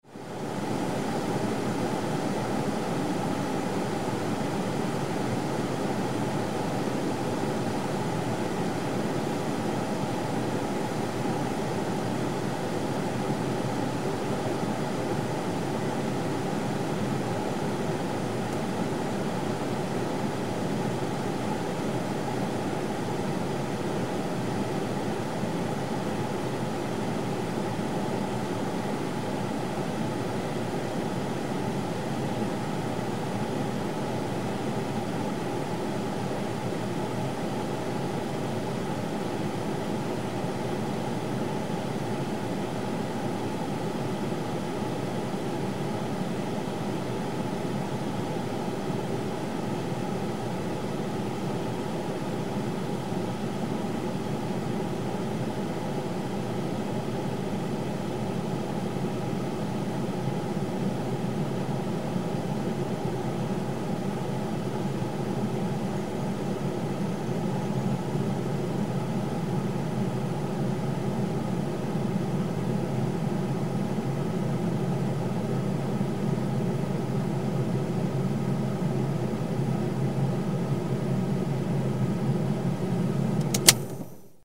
Звук работающего теплового вентилятора